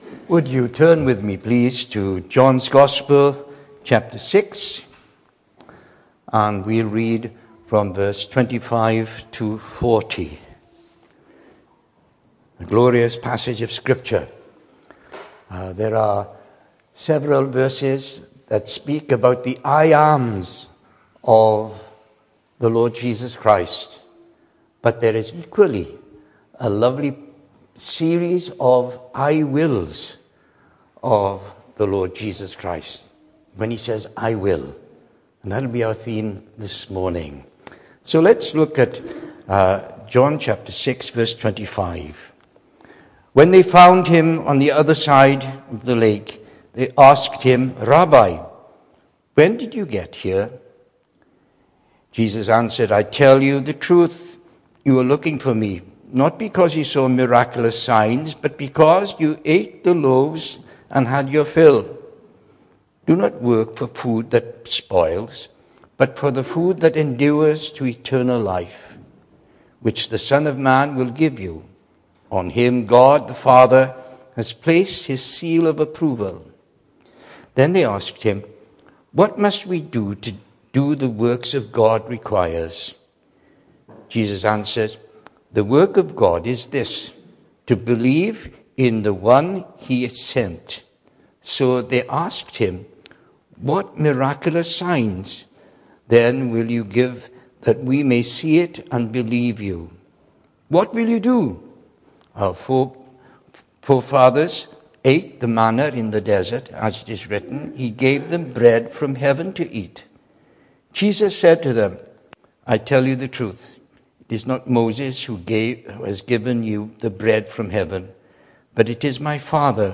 Hello and welcome to Bethel Evangelical Church in Gorseinon and thank you for checking out this weeks sermon recordings.
The 11th of September saw us host our morning service from the church building, with a livestream available via Facebook.